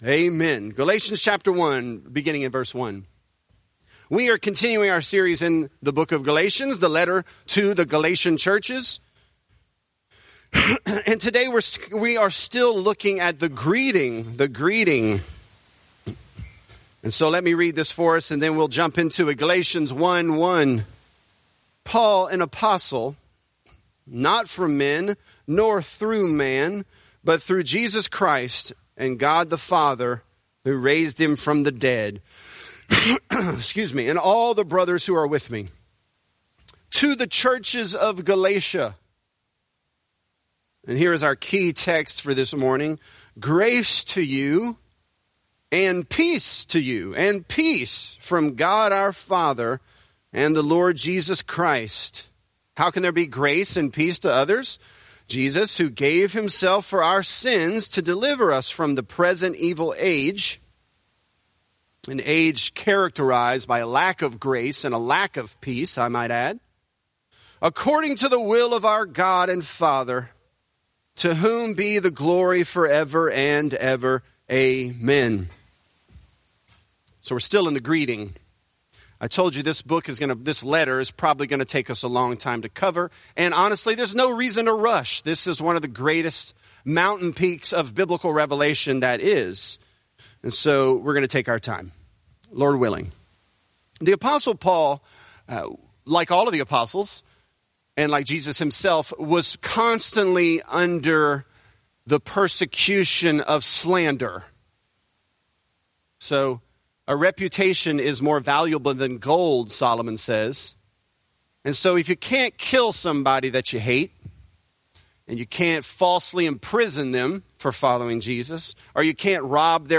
Galatians: Peace to You | Lafayette - Sermon (Galatians 1)